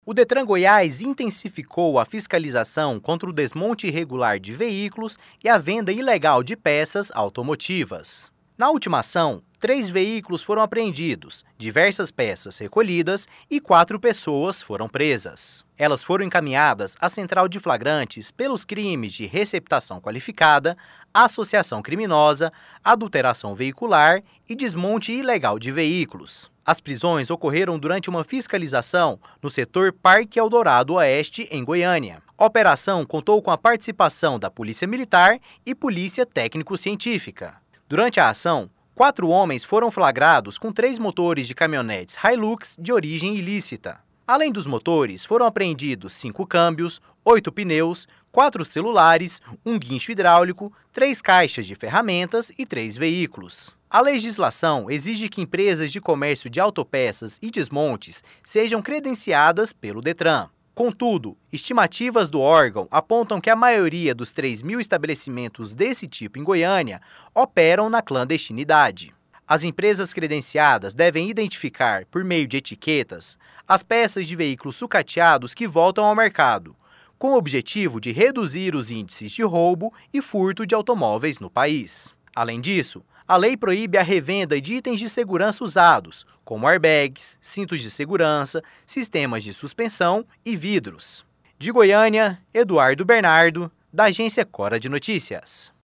Repórter